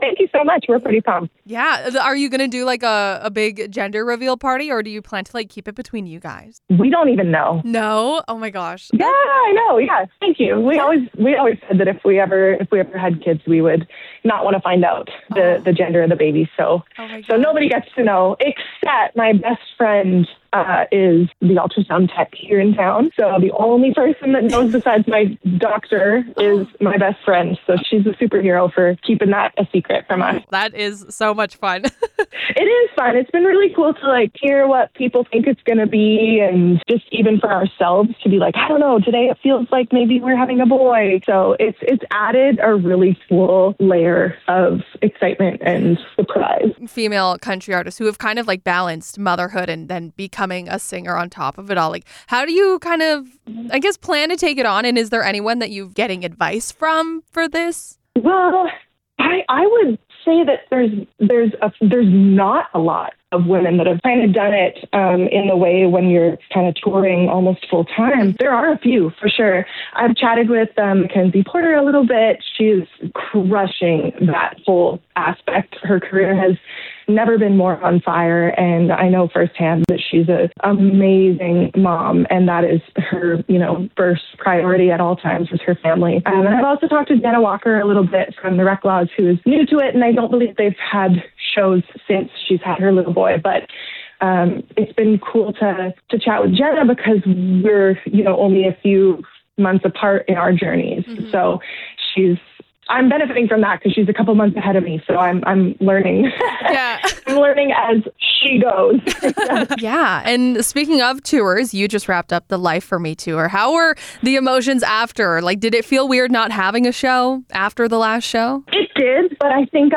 Jess is a Canadian country singer and songwriter from Saskatchewan, and made her debut with her album, Light Up the Night, which includes the platinum-certified single, Cheap Wine and Cigarettes.